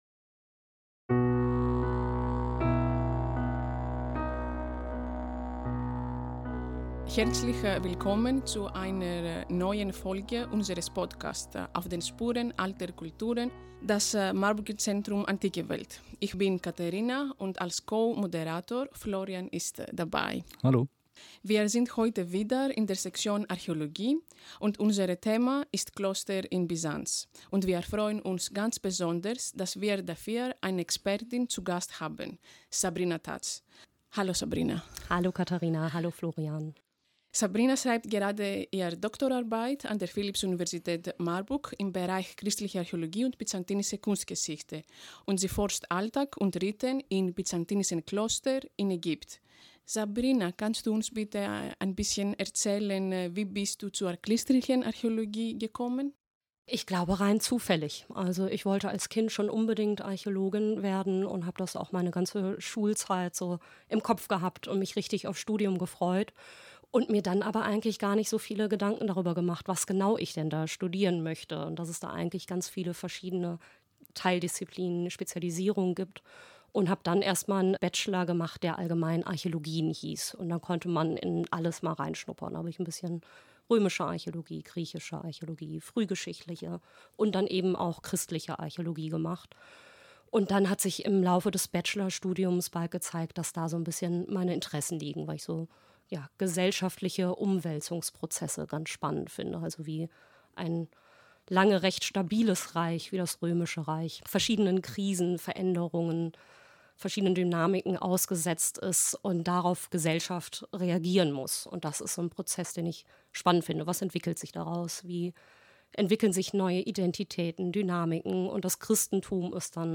Sie gibt Einblicke in archäologische Methoden, die besondere Lage des Friedhofs und die Rekonstruktion frühchristlicher Bestattungsrituale. Gemeinsam diskutieren wir, was die Gräber über das Leben, die Gemeinschaft und die religiöse Praxis der Mönche zwischen dem 6. und 10. Jahrhundert verraten.